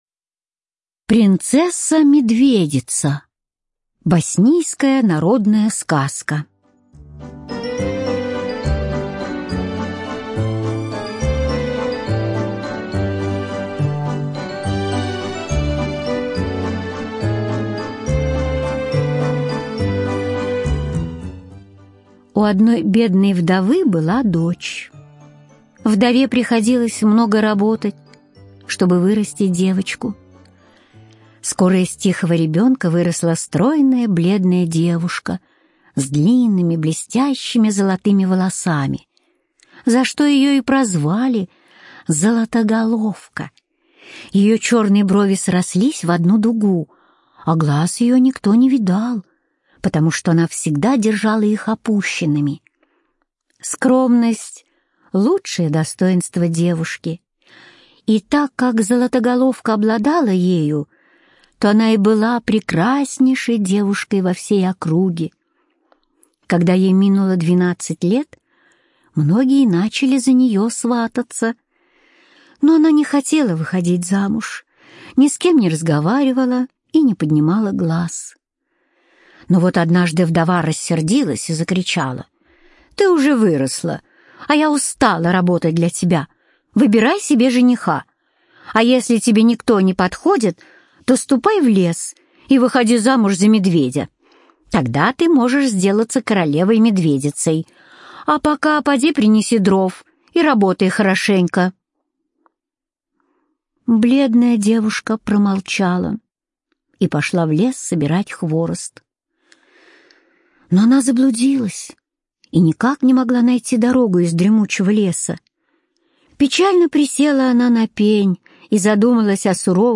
Принцесса-медведица - боснийская аудиосказка - слушать онлайн